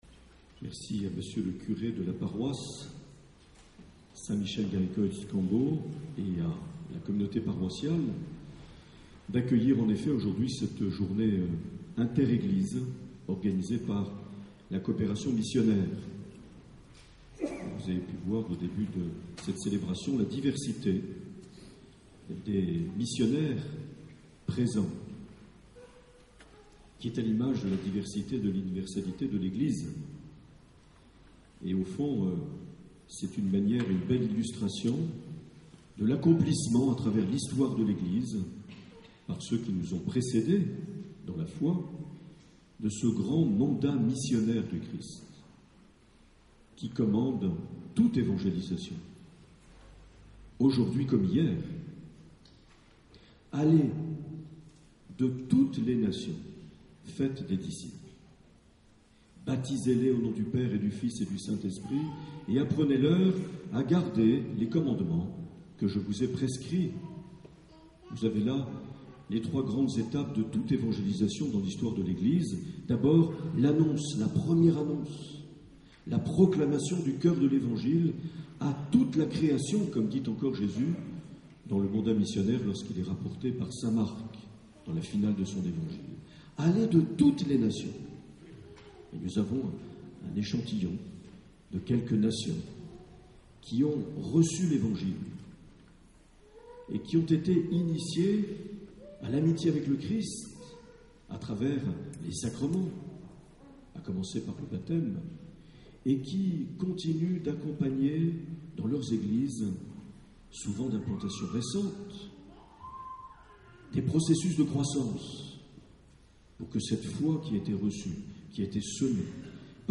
13 juillet 2014 - Eglise saint Laurent de Cambo - Journée Inter-Églises avec la Coopération Missionnaire
Accueil \ Emissions \ Vie de l’Eglise \ Evêque \ Les Homélies \ 13 juillet 2014 - Eglise saint Laurent de Cambo - Journée Inter-Églises avec (...)
Une émission présentée par Monseigneur Marc Aillet